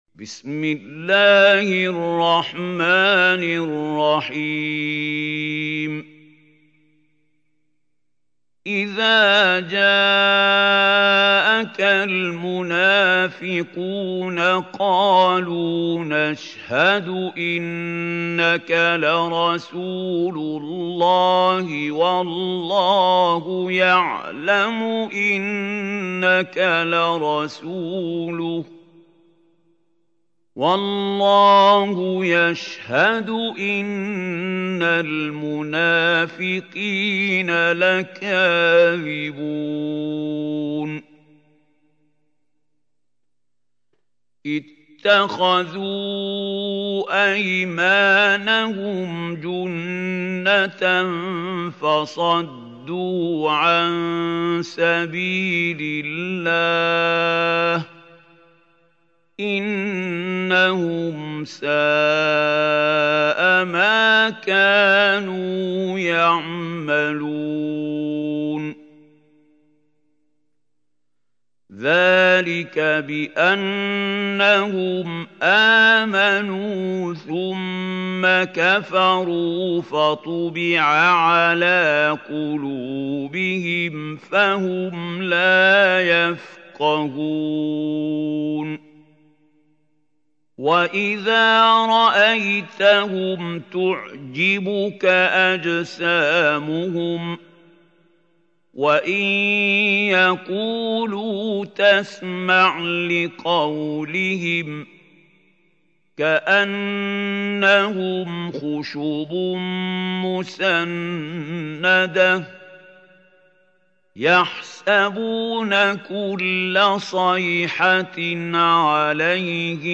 سورة المنافقون | القارئ محمود خليل الحصري